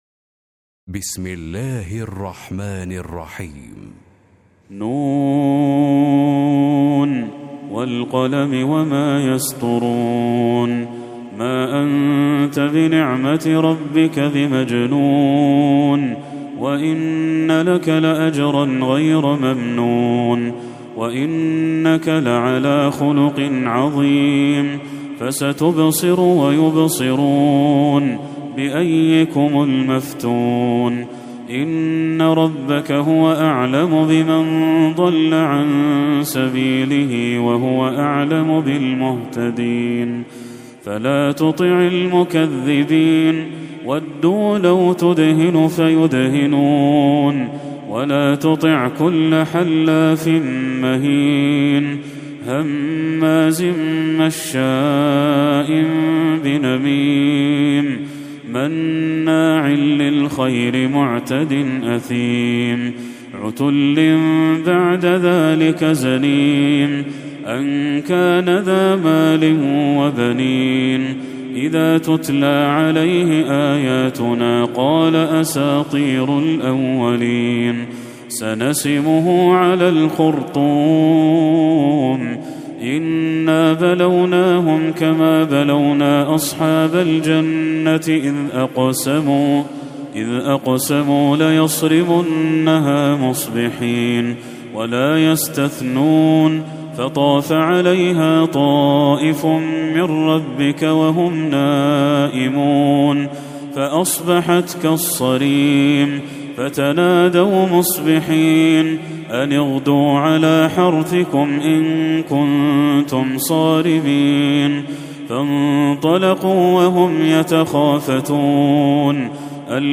سورة القلم Surat Al-Qalam > المصحف المرتل